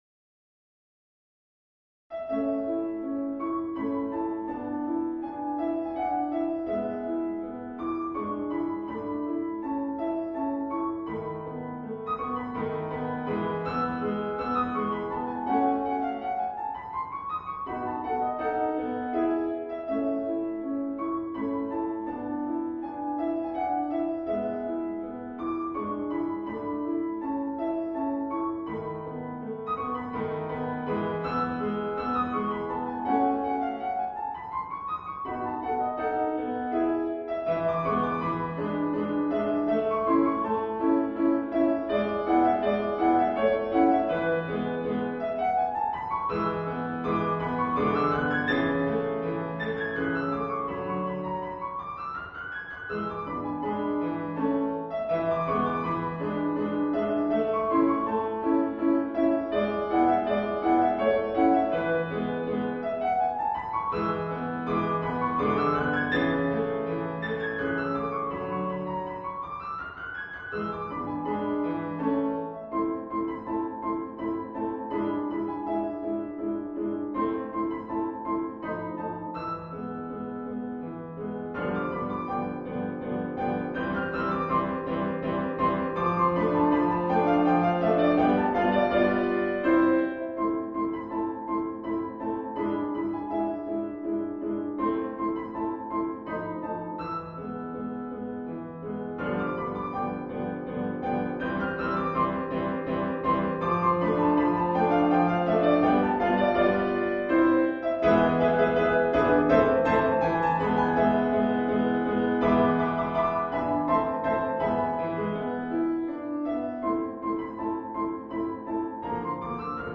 リピートは基本的に省略していますが、D.C.を含むものは途中のリピートも全て再現しています